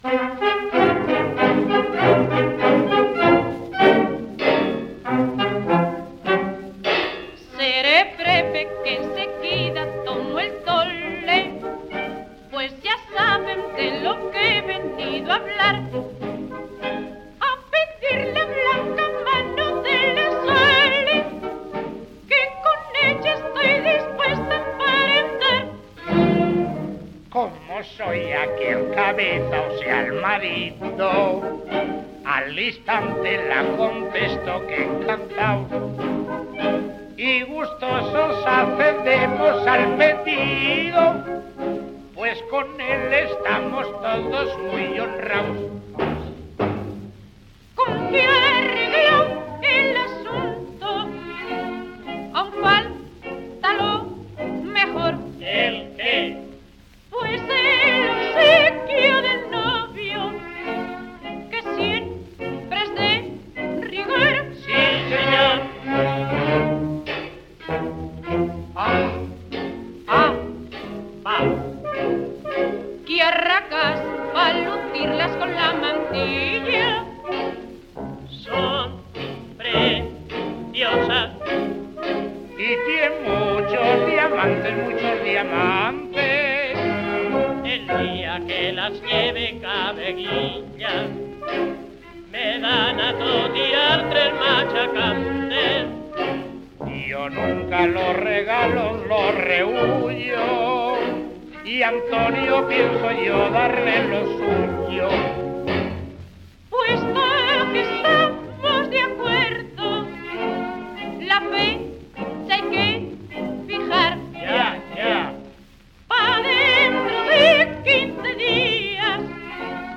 Chotis.
orquesta
78 rpm